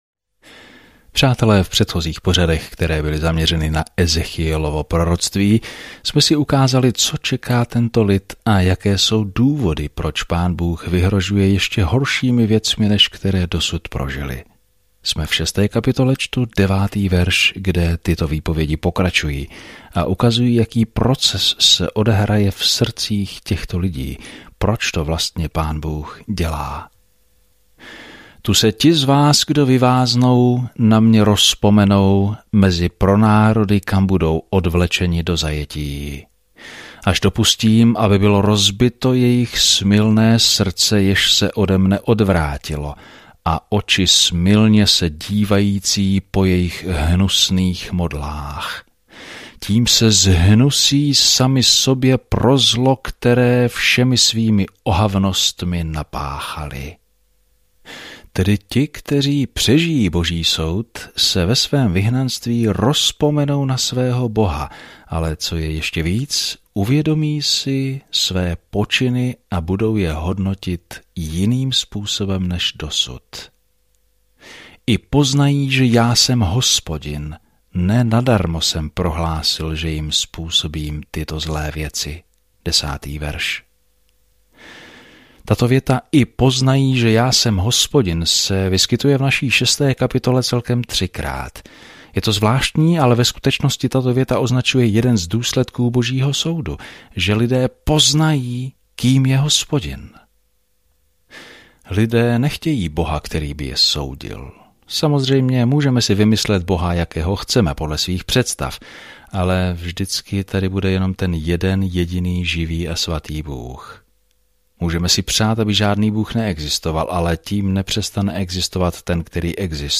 Písmo Ezechiel 6:9-14 Ezechiel 7 Ezechiel 8 Den 4 Začít tento plán Den 6 O tomto plánu Lidé nechtěli naslouchat Ezechielovým varovným slovům, aby se vrátili k Bohu, a tak místo toho předvedl apokalyptická podobenství a to probodlo srdce lidí. Denně procházejte Ezechielem a poslouchejte audiostudii a čtěte vybrané verše z Božího slova.